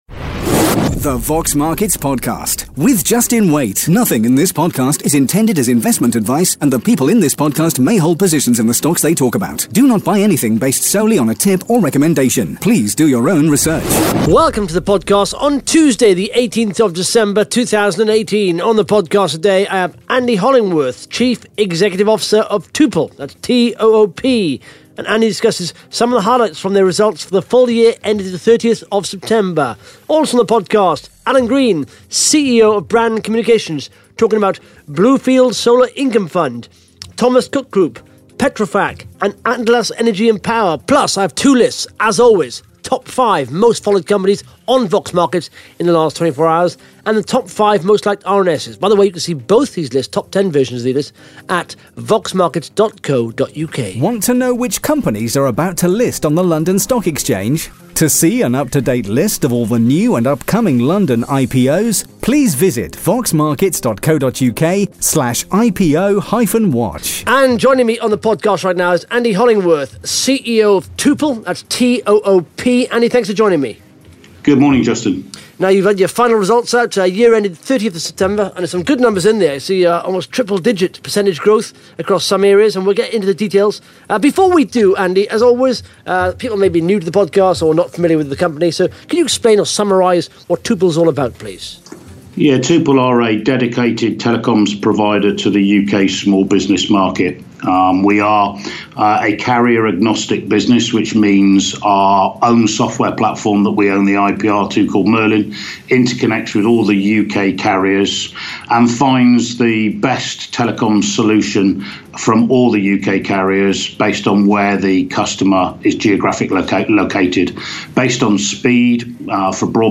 (Interview starts at 12 minutes 20 seconds) Plus the Top 5 Most Followed Companies & the Top 5 Most Liked RNS’s on Vox Markets in the last 24 hours.